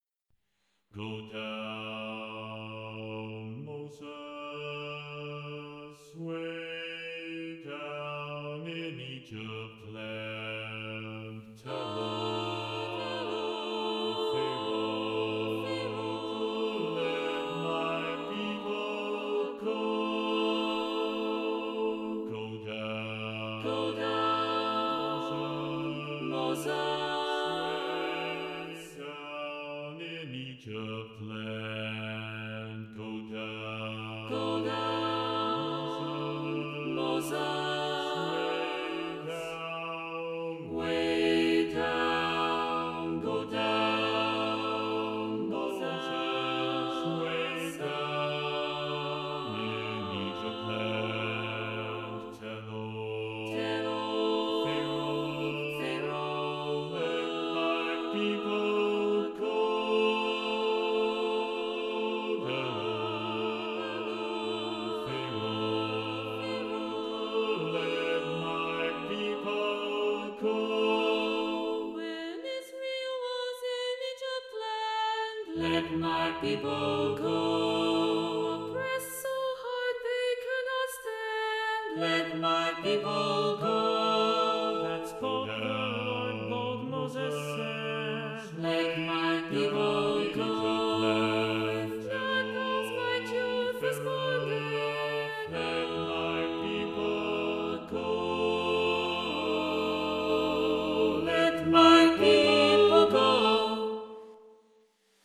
Number of voices: 1v Voicing: Baritone solo Genre: Sacred, Spiritual, Art song
Language: English Instruments: Piano
Audio provided is Cantamus app virtual choir performance.